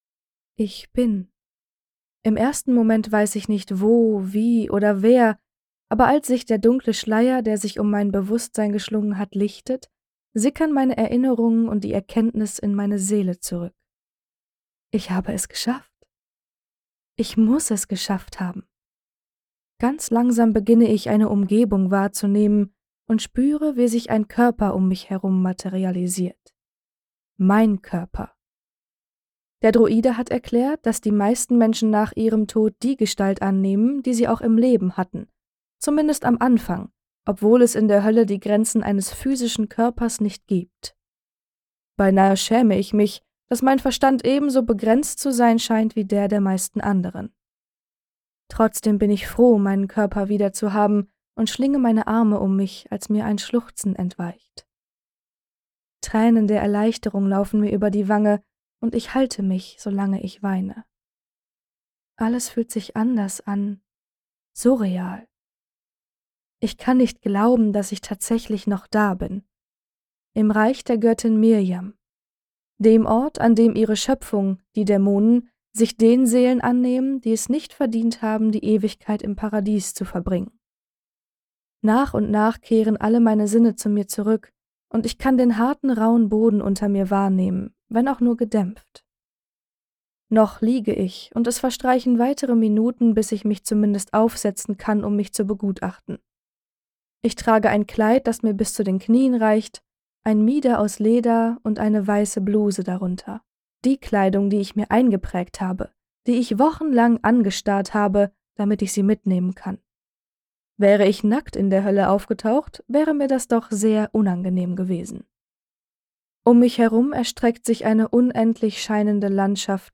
Hörbuch | Fantasy